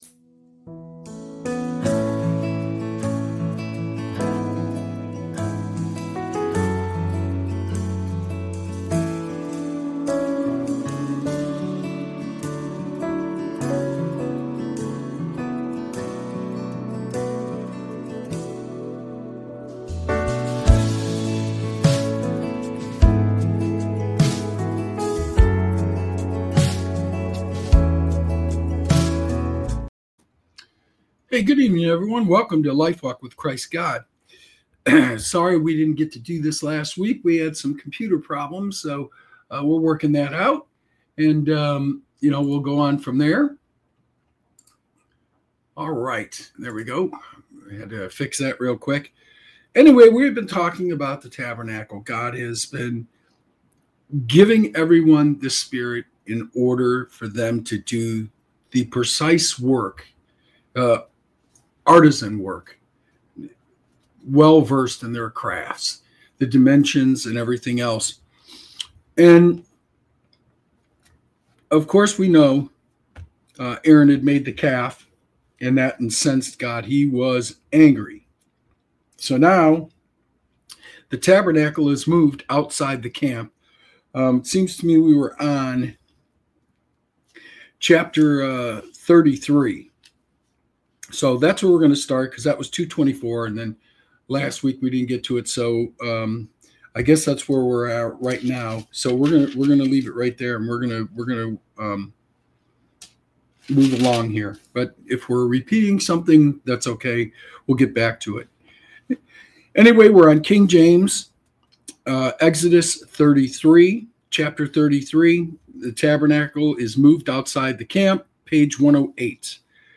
This show offers a reading into the teachings of Jesus Christ, providing insights into the Bible.